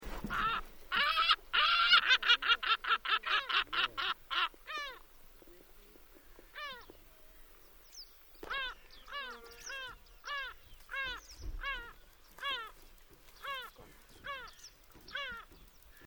Gulls in Korgalzyn reserve, Akmola area, Kazakstan
Two examples of cachinnans-like calls:
Call 2 (157 KB)